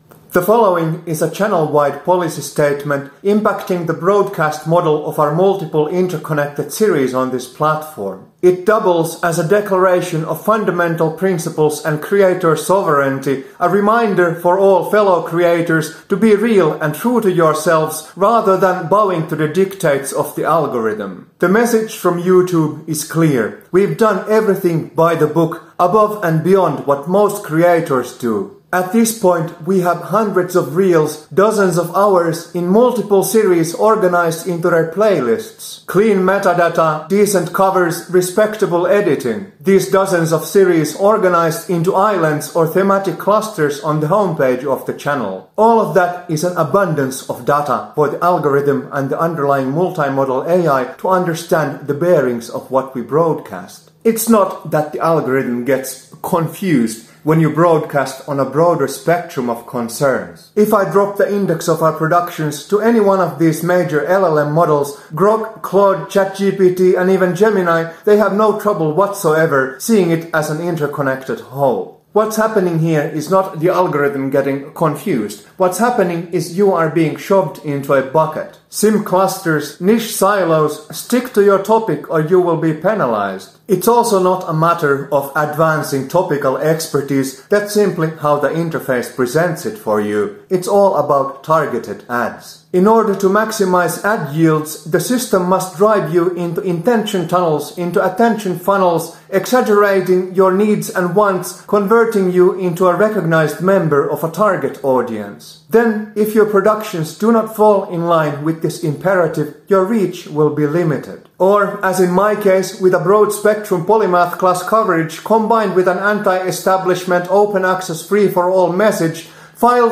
This is a channel-wide broadcast policy statement and declaration of creator sovereignty.